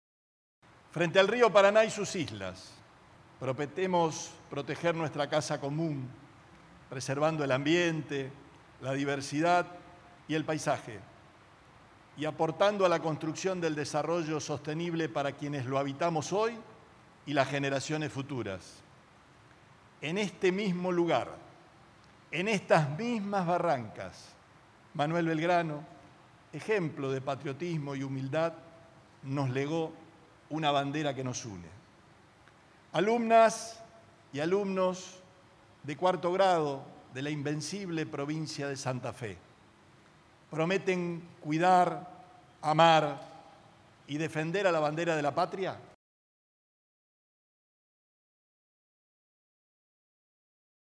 El gobernador Omar Perotti encabezó este viernes en la ciudad de Rosario el acto de juramento de lealtad a la bandera a alumnos y alumnas de 4° grado de escuelas de todo el territorio de la provincia, en el marco del Día de la Bandera.
Gobernador Perotti.